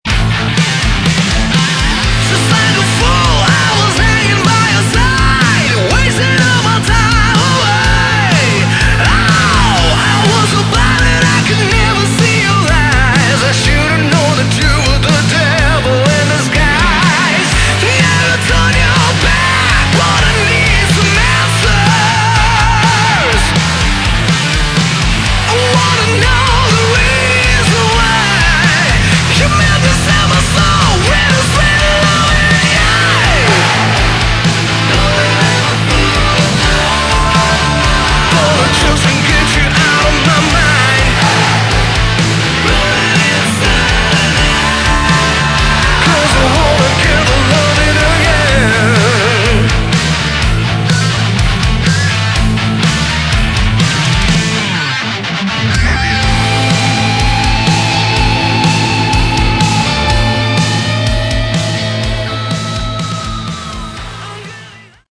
Category: Hard Rock
Vocals, Guitars
Lead Guitars